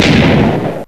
old_explode.ogg